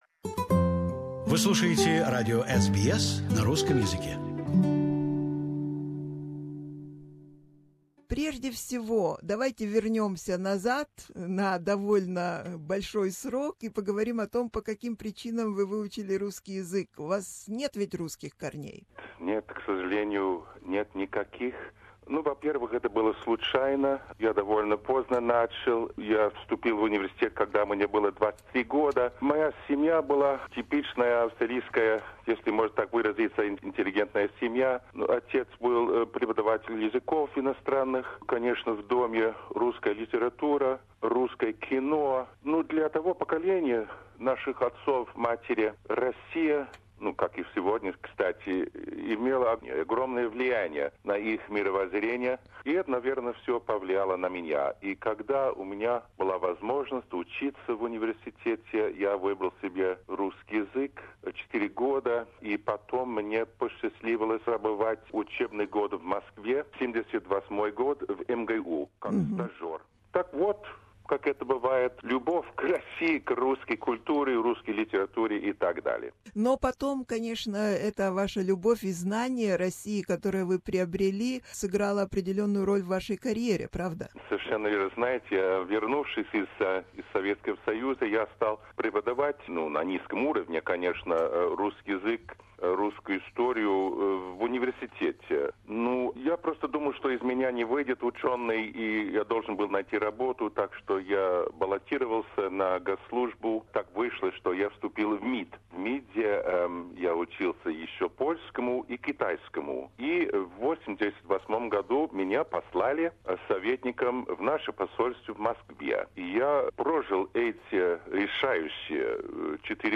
поразительный разговор